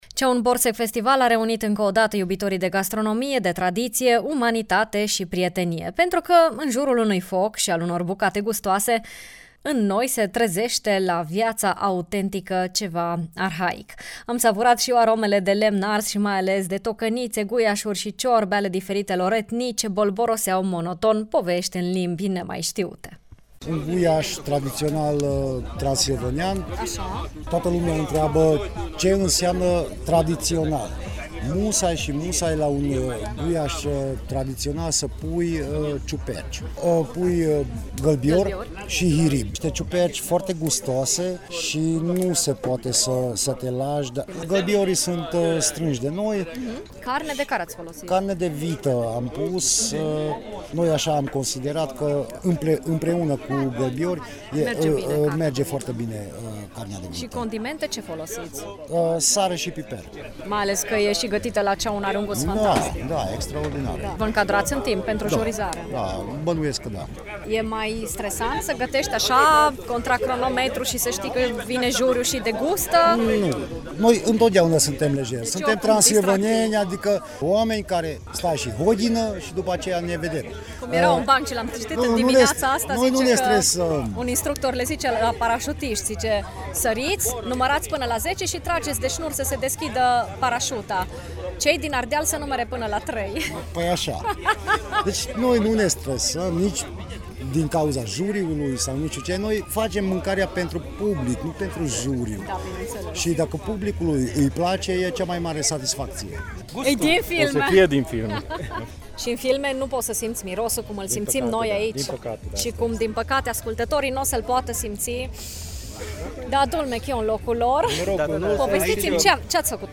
Gusturi pasionale și arome de condimente, voie bună și muzică de nu-ți puteai ține picioarele cuminți, prietenie și veselie. Cam aceasta a fost editia Cea1 Borsec Festival a acestui an: